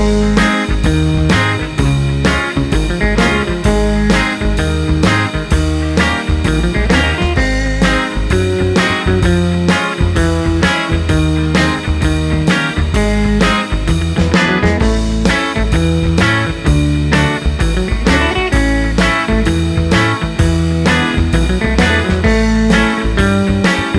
no Backing Vocals Soul / Motown 2:30 Buy £1.50